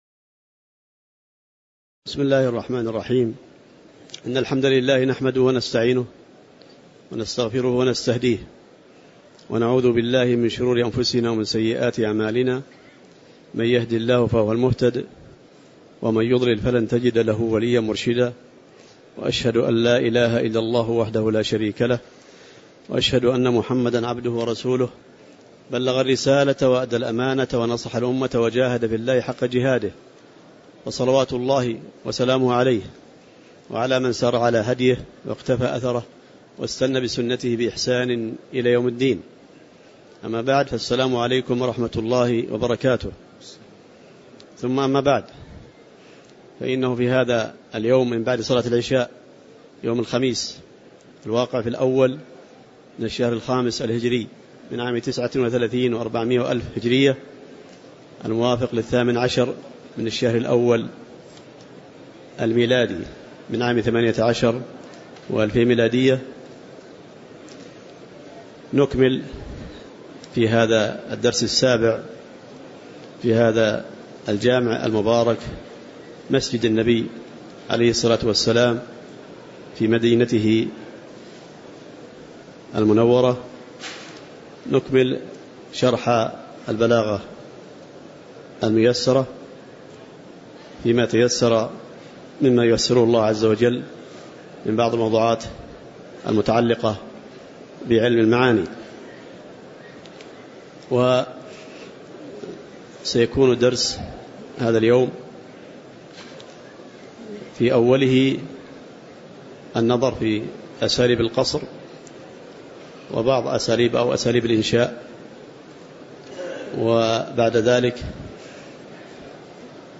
تاريخ النشر ١ جمادى الأولى ١٤٣٩ هـ المكان: المسجد النبوي الشيخ